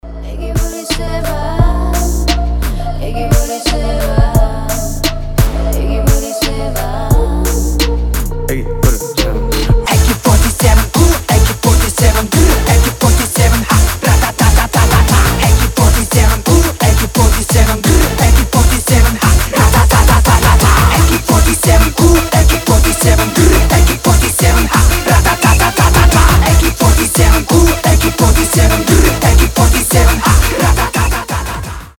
• Качество: 320, Stereo
Trap
энергичные
красивый женский голос
быстрые
Hard dance
Rave